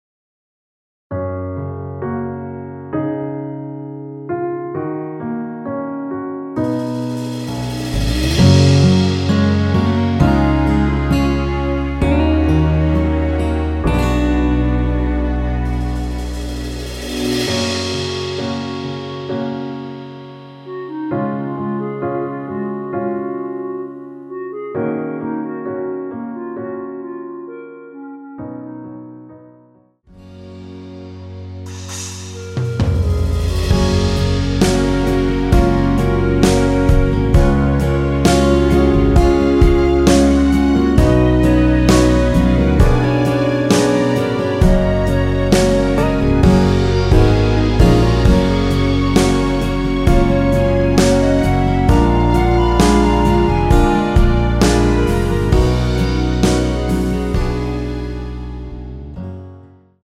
원키에서(-1)내린 멜로디 포함된 MR입니다.
F#
앞부분30초, 뒷부분30초씩 편집해서 올려 드리고 있습니다.
중간에 음이 끈어지고 다시 나오는 이유는